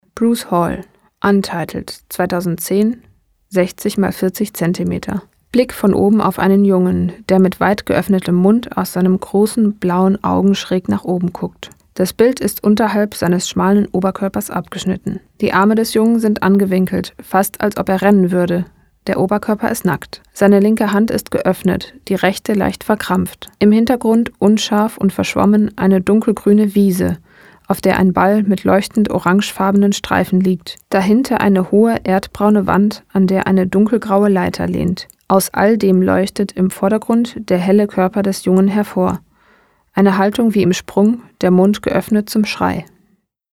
Dieses Bild wurde im Rahmen einer Ausstellung zur Filmpremiere des Dokumentarfilms „Shot in the Dark“ in der Brotfabrik Berlin gezeigt. Der Text stammt aus dem großartigen Audio Guide zur Ausstellung.